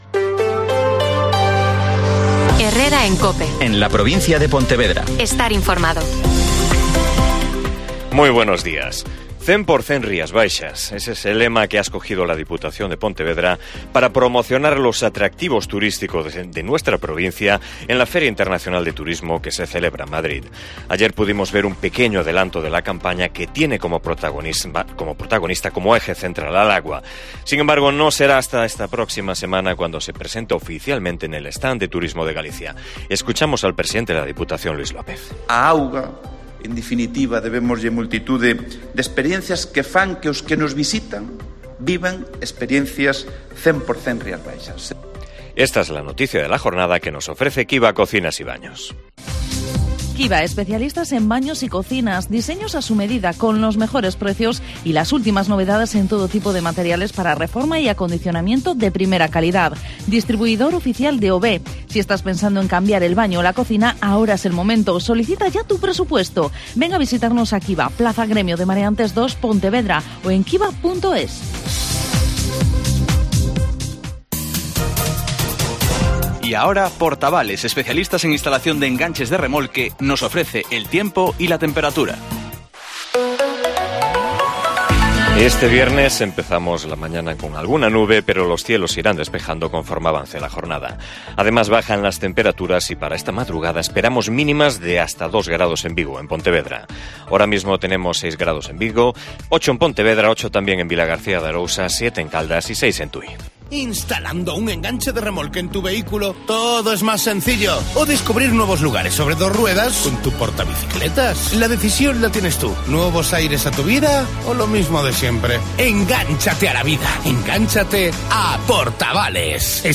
Herrera en COPE en la Provincia de Pontevedra (informativo 08:24h)